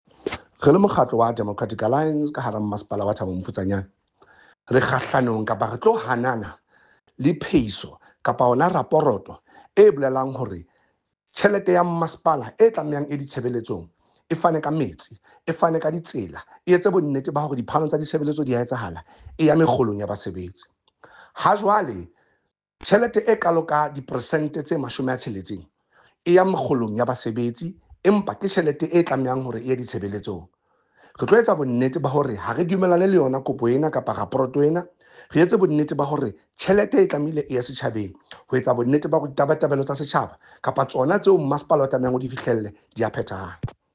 Sesotho soundbites by Cllr Eric Motloung and